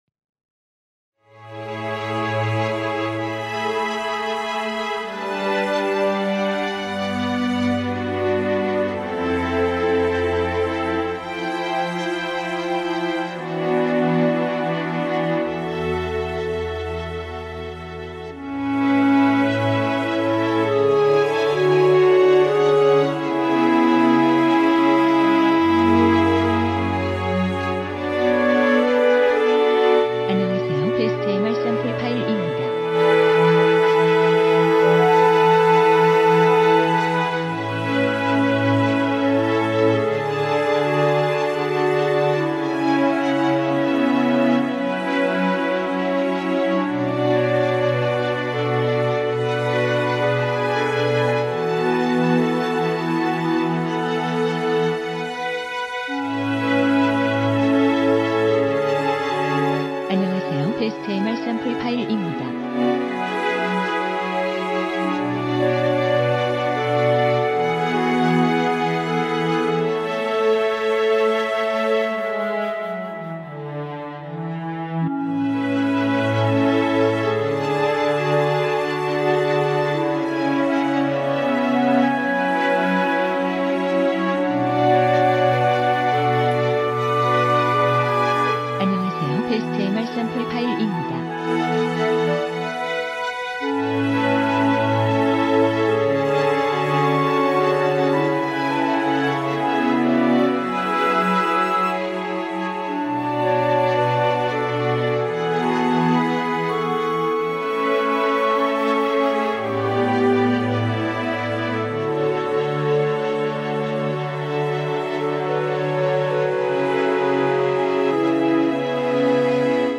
원키(1절앞+2절후렴) 진행되는 멜로디 포함된 MR입니다.(미리듣기 확인)
앞부분30초, 뒷부분30초씩 편집해서 올려 드리고 있습니다.
중간에 음이 끈어지고 다시 나오는 이유는